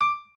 pianoadrib1_61.ogg